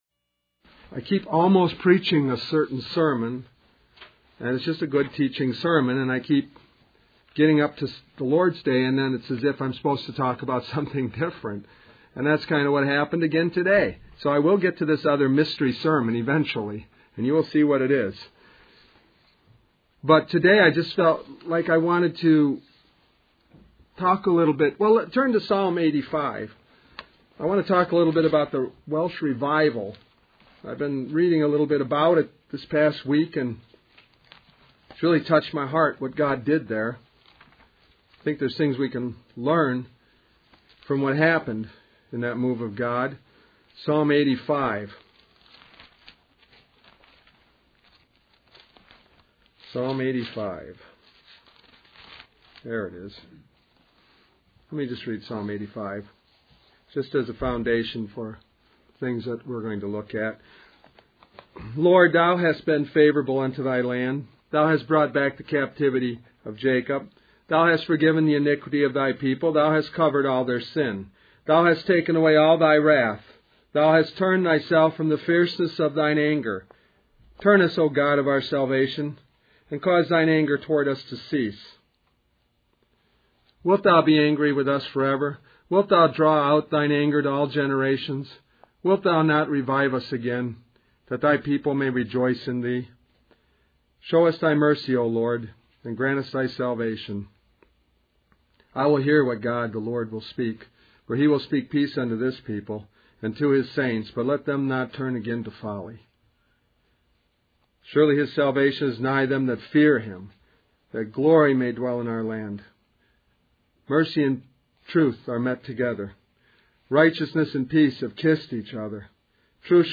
In this sermon, the preacher emphasizes the need for individuals to acknowledge and repent for wasting their lives. He encourages humility and acceptance of wrongdoing, urging listeners to allow God to heal and forgive them. The preacher expresses a deep desire for revival and for God to move powerfully in people's hearts.